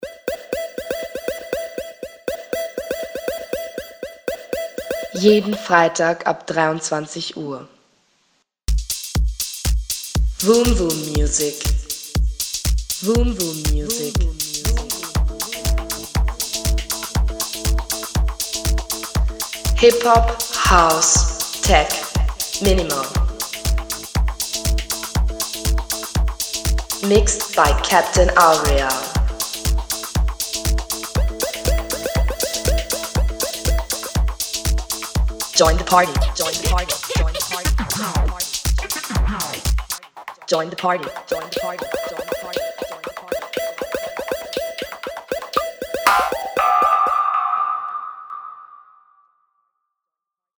Sendungstrailer
FRS-TRAILER-VOOMVOOM-MUSIC-JEDEN-FREITAG.mp3